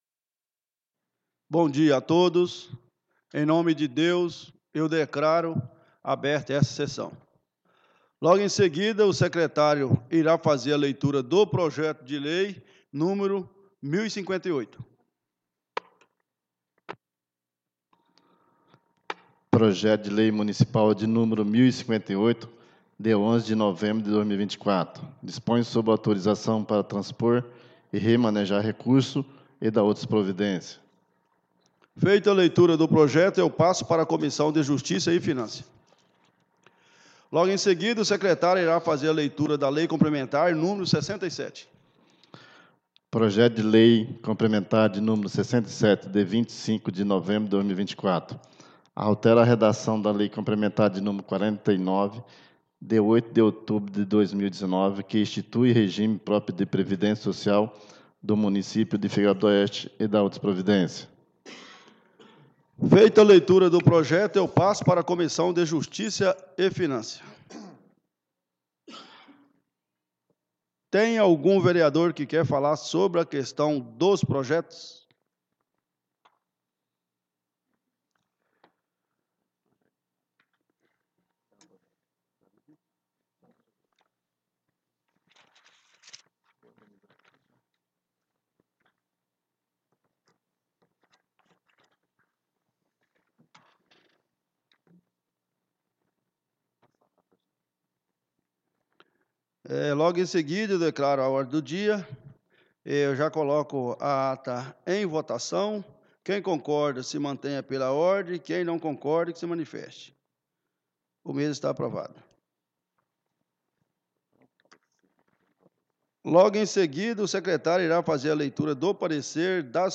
18° SESSÃO ORDINÁRIA DO DIA 02 DE DEZEMBRO DE 2024